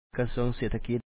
kasúaŋ sêe thakit Ministry of National Economy